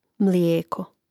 mlijéko mlijeko